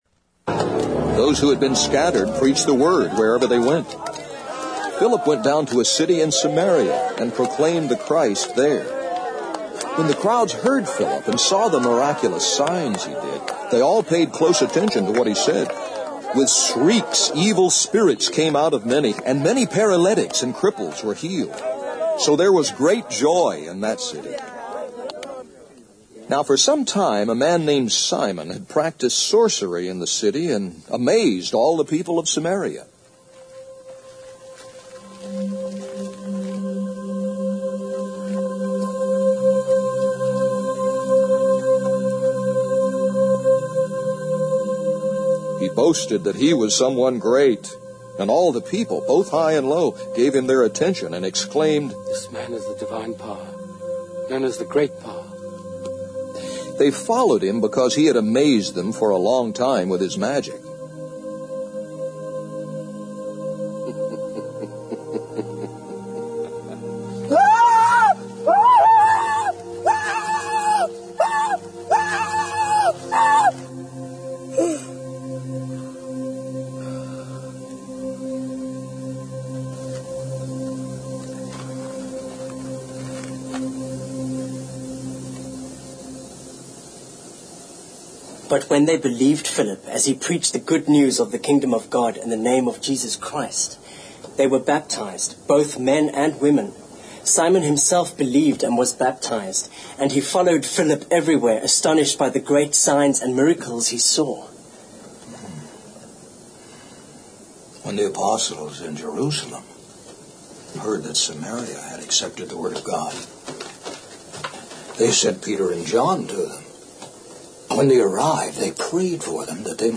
First Baptist Church Sermons